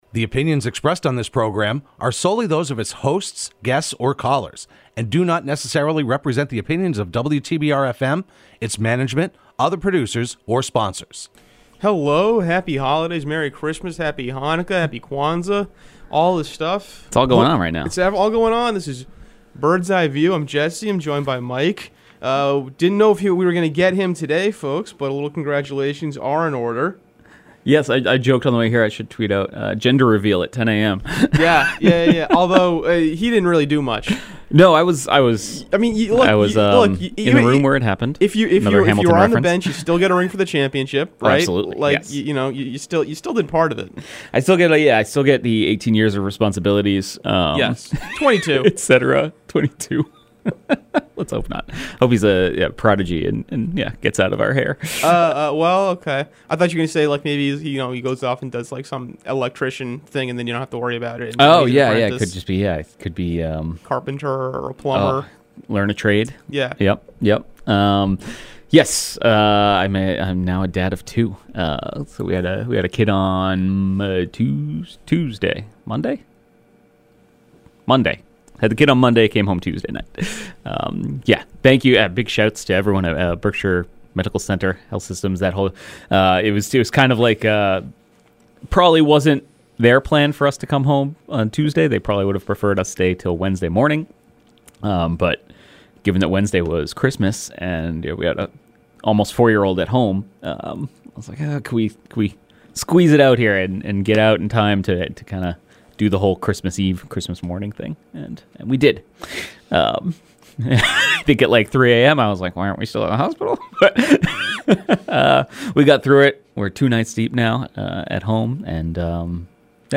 Hosted by the Berkshire Eagle Sports team and broadcast live every Thursday morning at 10am on WTBR.